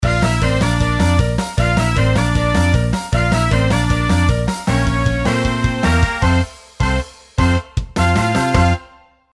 楽曲の曲調： MIDIUM
ジングルです。